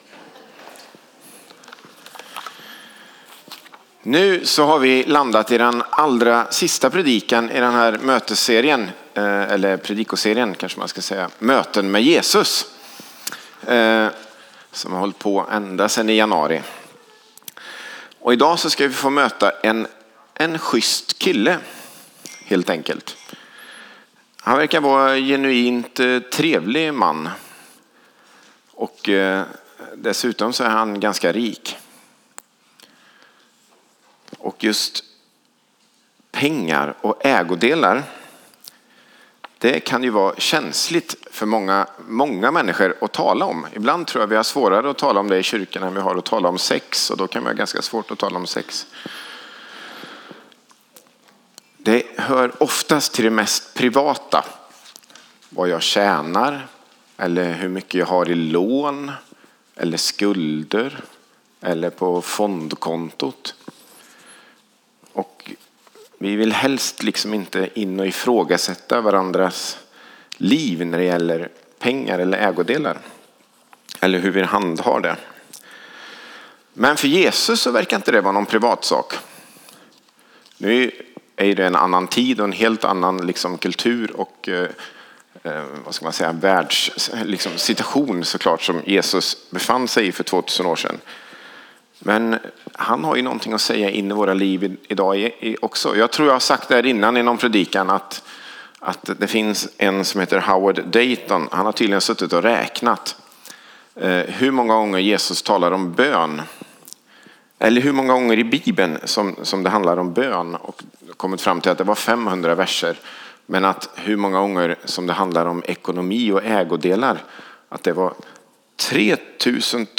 Predikan - Skogsrokyrkan